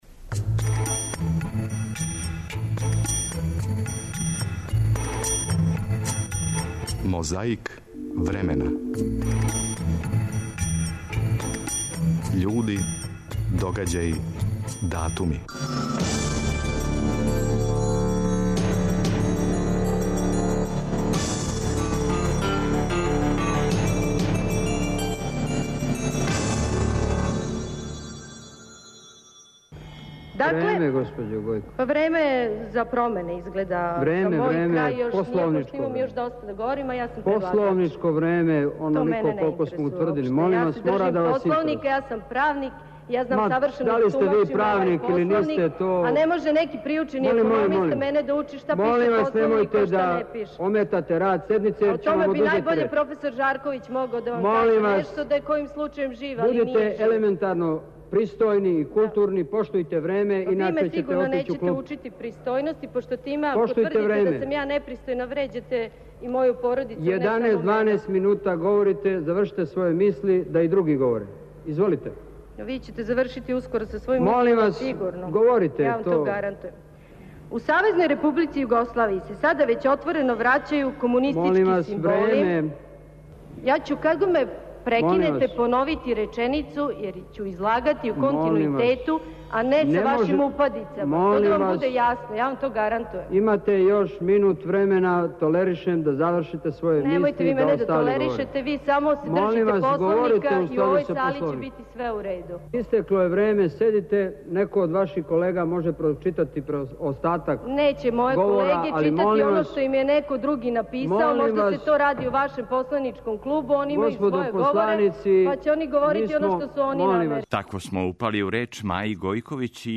Учесници Радоман Божовић као председавајући и Маја Гојковић као посланица.
Вук Драшковић је гостовао у студију 1 Радио Београда, 3. новембра 1990. године.
Ако будете пажљиво слушали, чућете како је, том приликом, Јосип Броз певао Интернационалу.
Подсећа на прошлост (културну, историјску, политичку, спортску и сваку другу) уз помоћ материјала из Тонског архива, Документације и библиотеке Радио Београда.